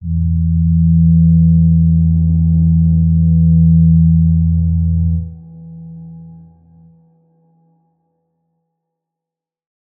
G_Crystal-E3-mf.wav